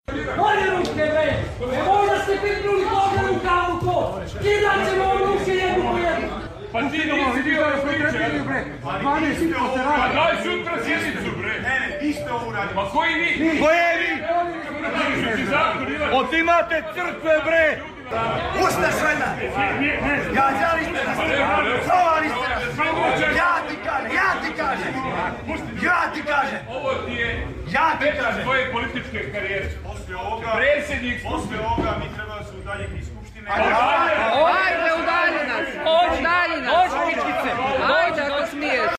Tonski snimak incidenta u Skupštini